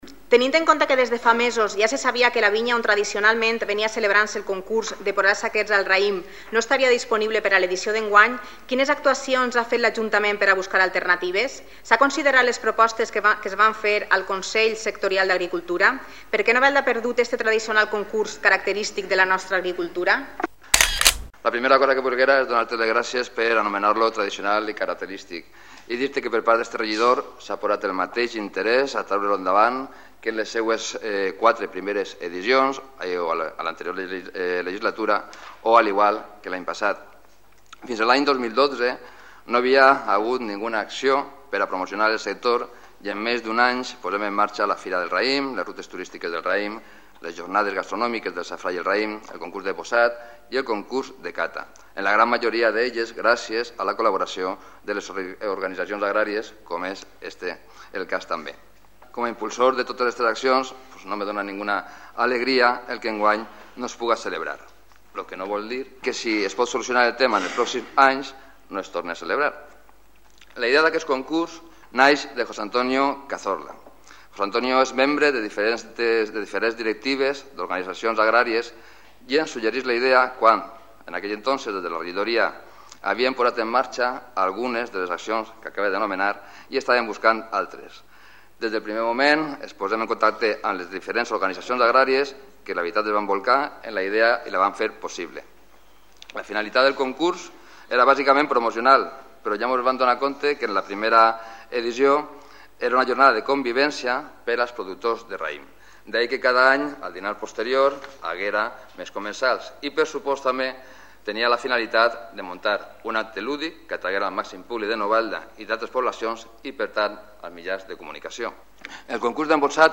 La dificultad para encontrar un lugar que reúna las condiciones adecuadas ha sido la causa de la suspensión de la edición 2018 del Concurso de Embolsado de Uva de Mesa organizado por el Ayuntamiento y organizaciones agrarias, según señaló el concejal de Agricultura y Turismo, Francisco Sepulcre, en la sesión plenaria del Ayuntamiento.